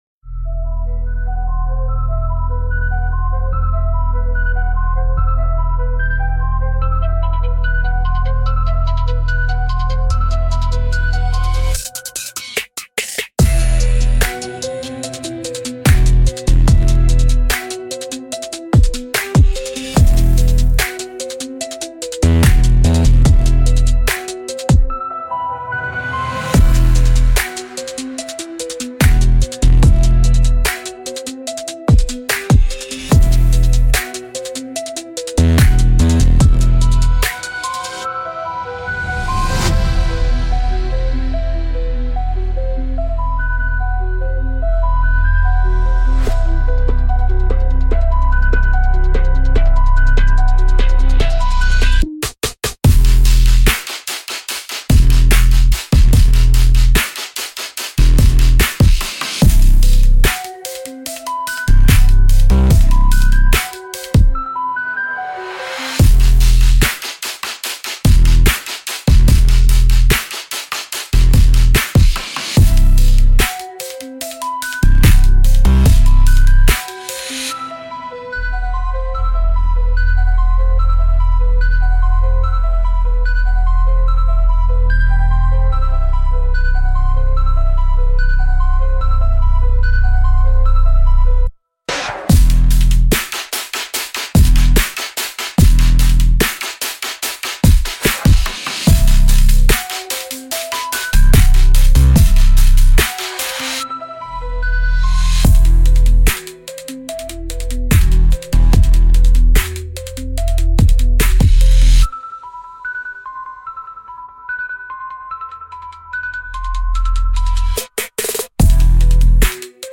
Instrumental - Glitch in the Swamp Matrix